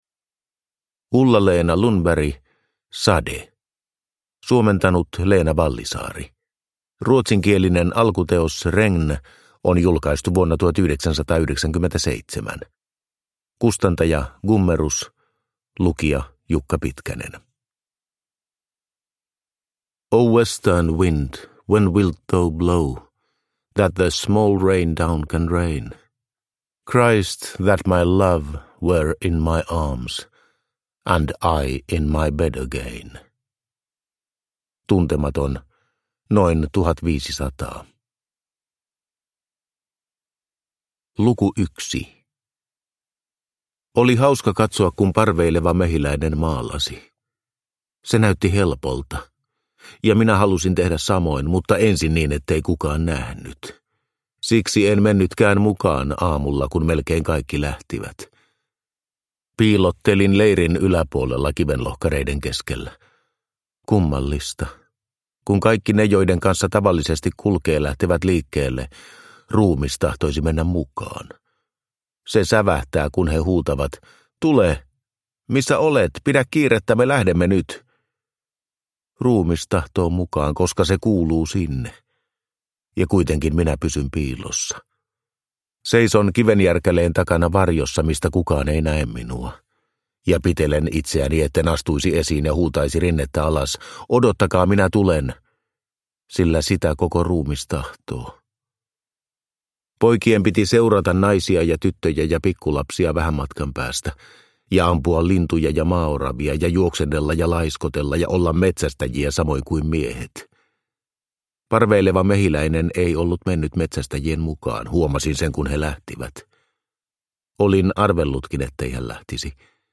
Sade – Ljudbok – Laddas ner